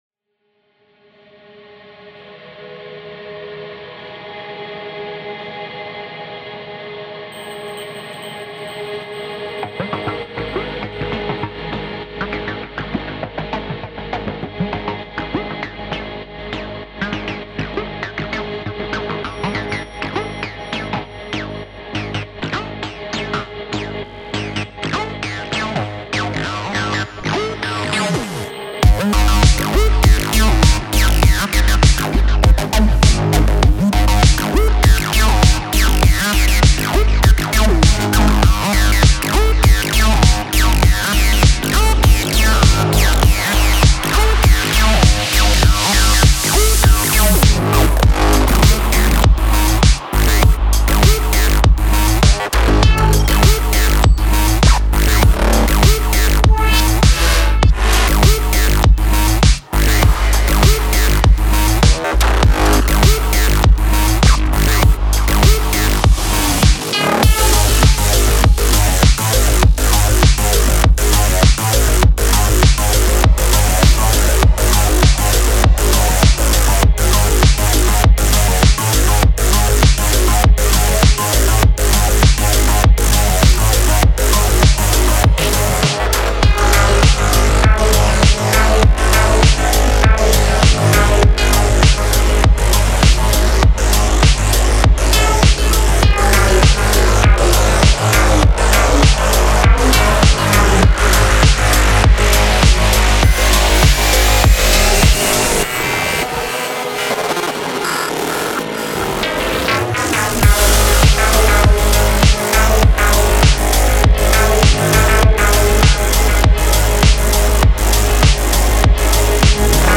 デモサウンドはコチラ↓
Genre:Synthwave
69 Synth Loops